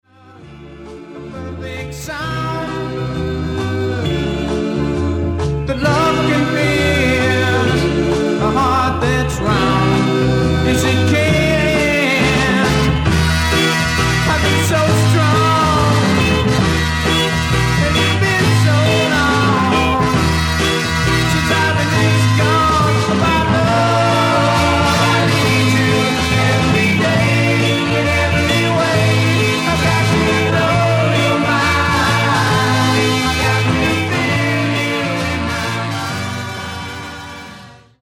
SOFT ROCK / PSYCHEDERIC POP